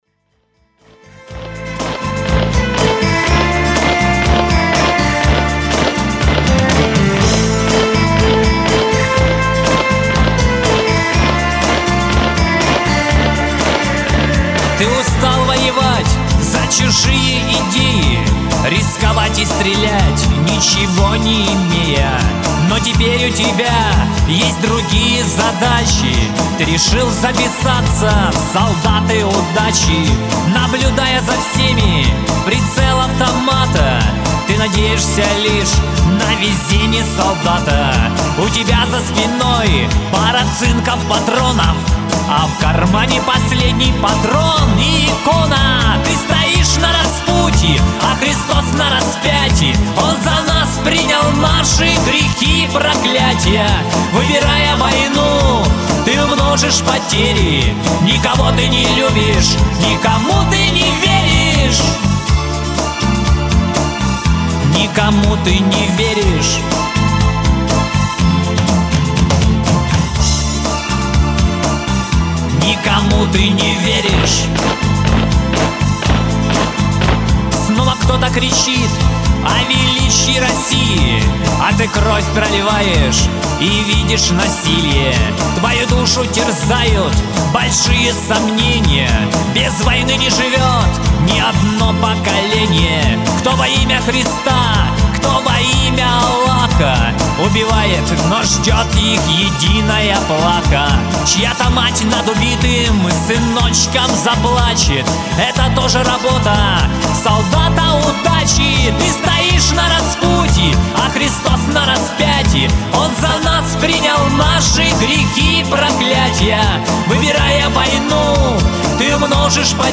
Сегодня у нас состоялся праздник предстоящего дня ВДВ.
И, как обычно, была и солдатская каша, и показательные выступления десантуры, и прыжки парашютистов, и концерт!
Вначале он спел вместе с группой из Смоленска "Трассера".